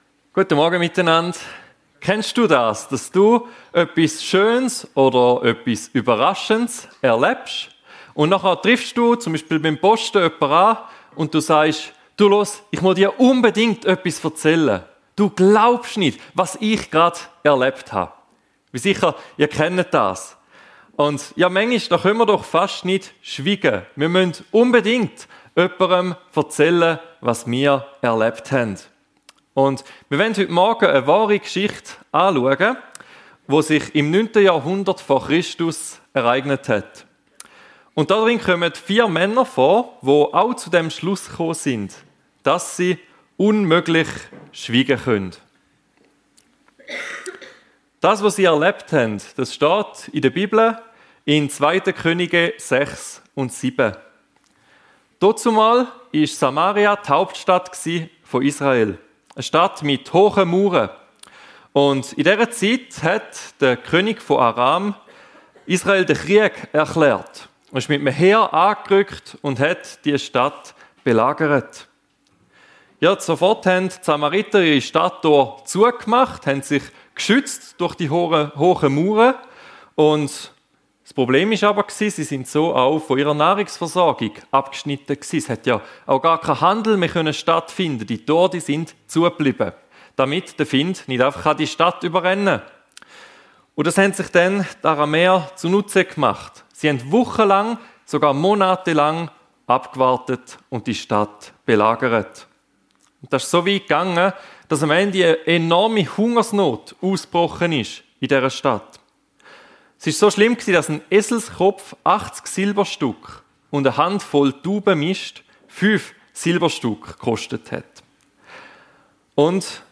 Wir können nicht schweigen ~ FEG Sumiswald - Predigten Podcast